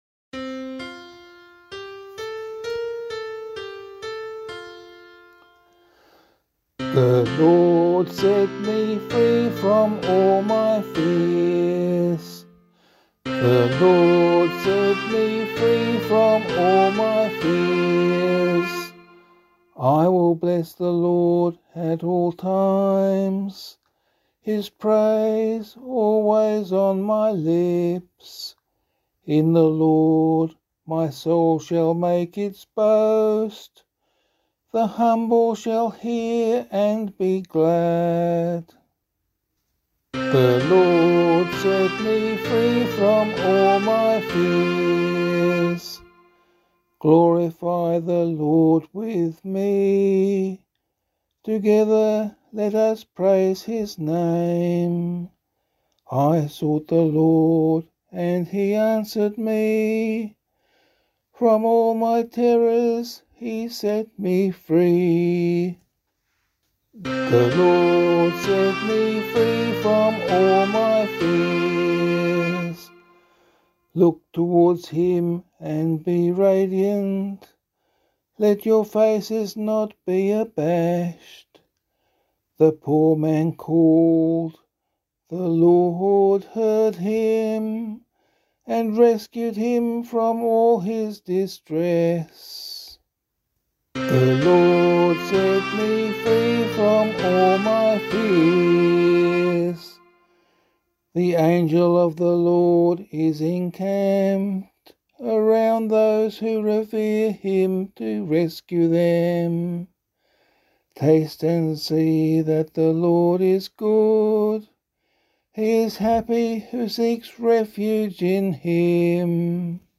280 Peter and Paul Day Psalm [LiturgyShare 6 - Oz] - vocal.mp3